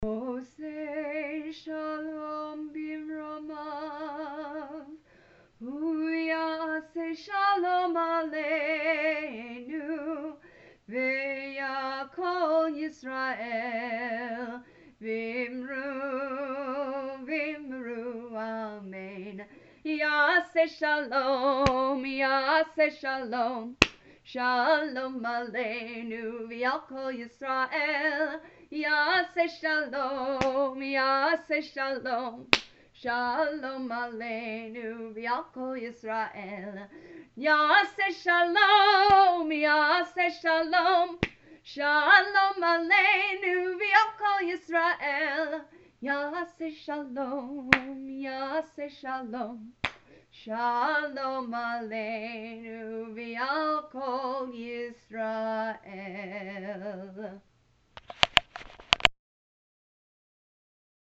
Traditional tune for Oseh Shalom